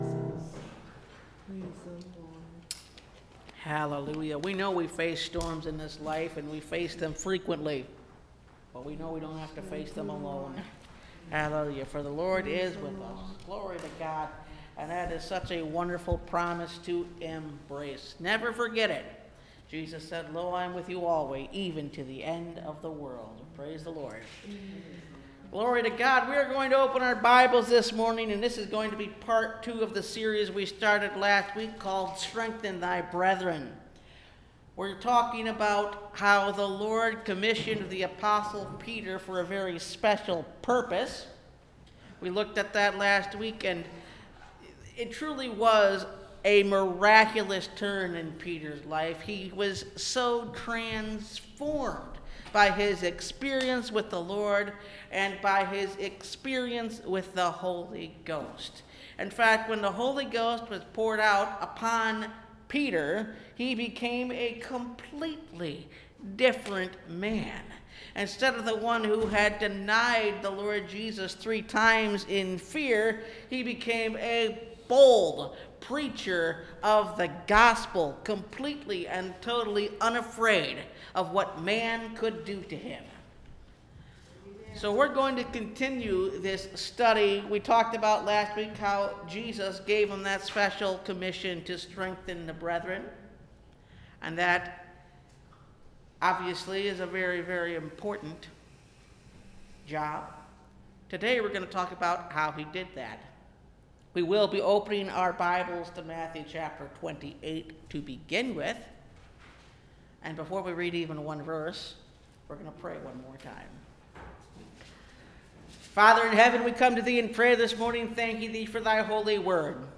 Strengthen Thy Brethren – Part 2 (Message Audio) – Last Trumpet Ministries – Truth Tabernacle – Sermon Library